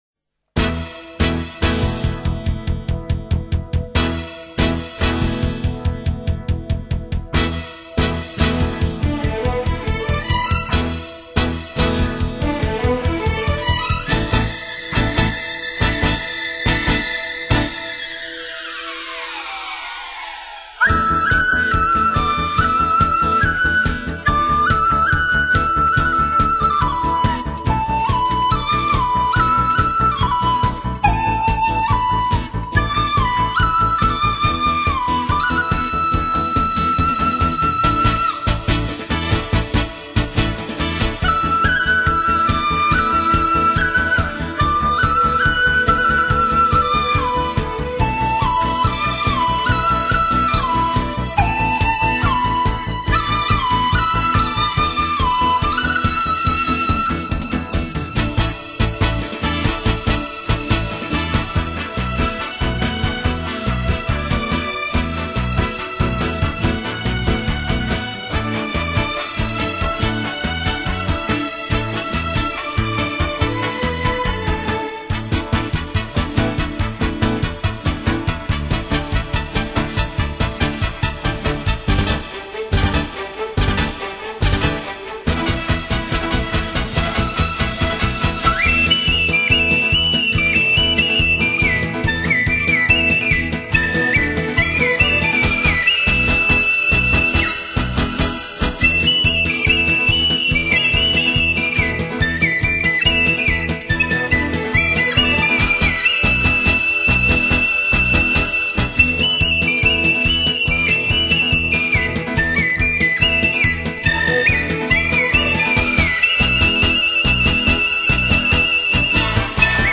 首部中国民乐交响乐